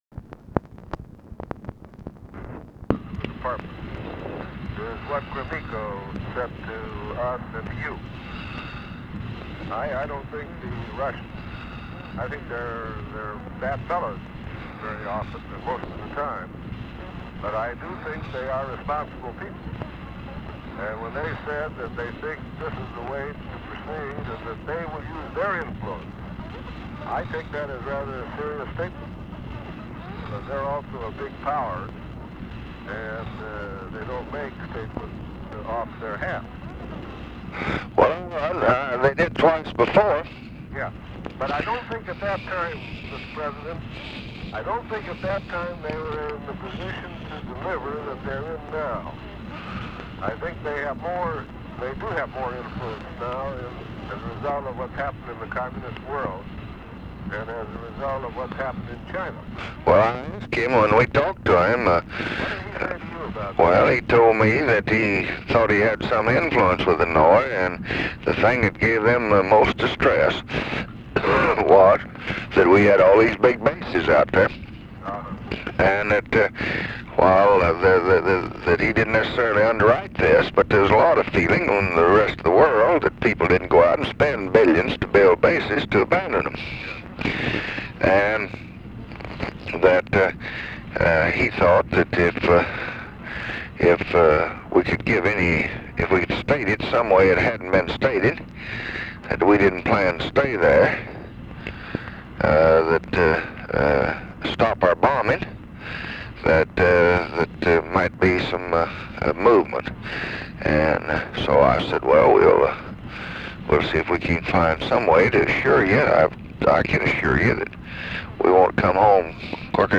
Conversation with ARTHUR GOLDBERG, December 31, 1966
Secret White House Tapes